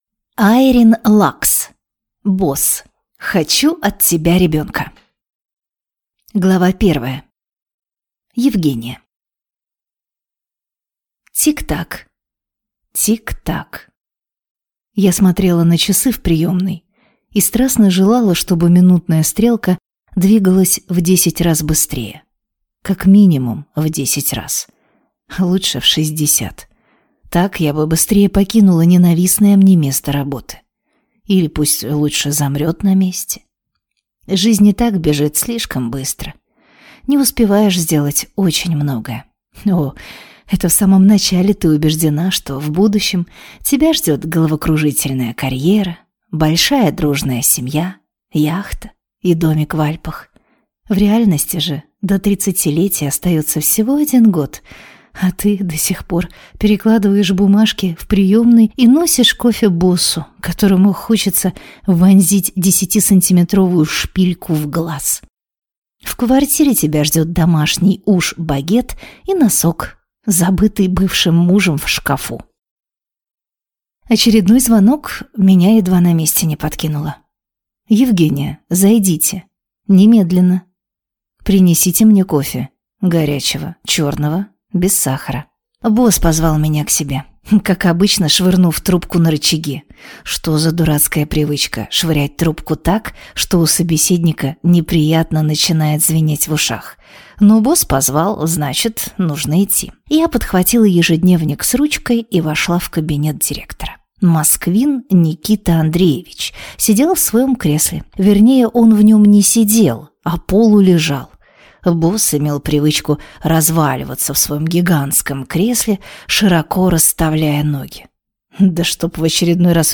Аудиокнига Босс, хочу от тебя ребенка | Библиотека аудиокниг